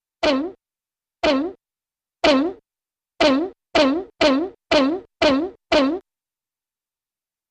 Object Bounces, 2 Slow, 7 Fast